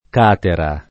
Catera [ k # tera o kat $ ra ] pers. f. (= Caterina)